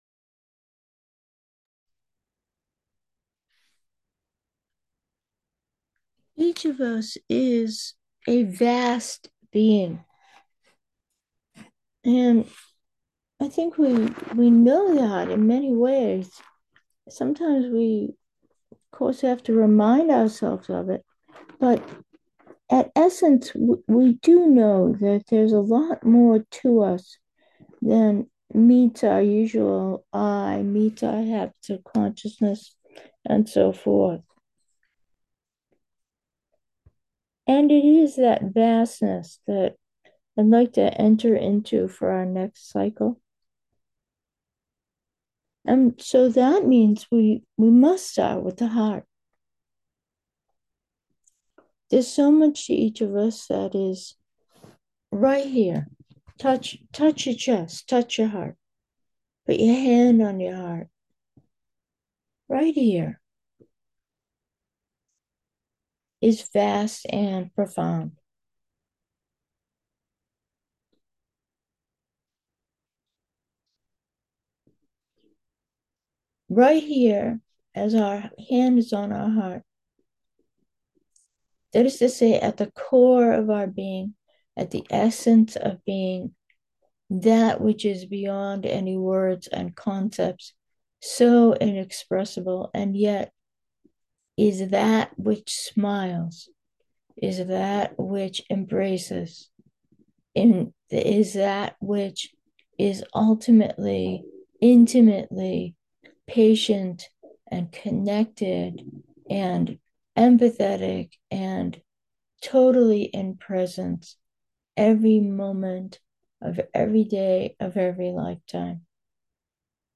Meditation: heart 1